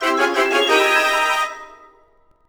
laser_panic_01.wav